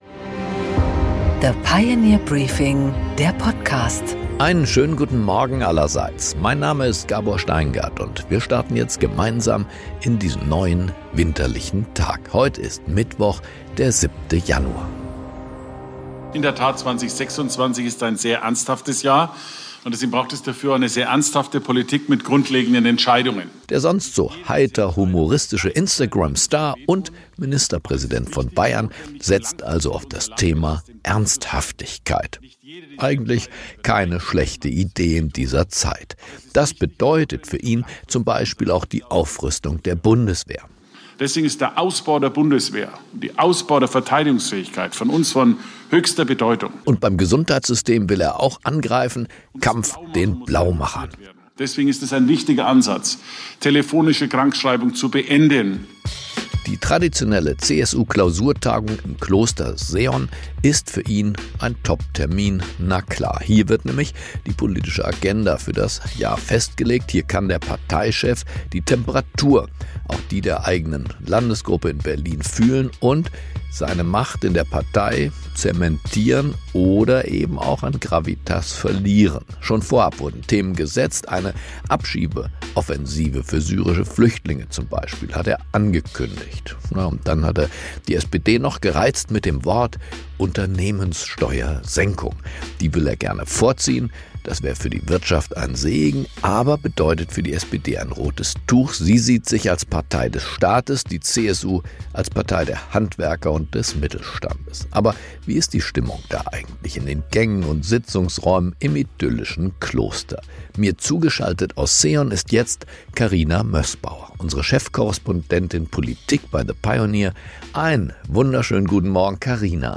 Im Gespräch: David Petraeus, Ex‑CIA‑Direktor und Ex-Vier‑Sterne‑General, bewertet im Gespräch mit Gabor Steingart im Interview die außen‑ und sicherheitspolitische Strategie der USA unter Donald Trump.